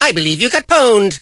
mortis_kill_04.ogg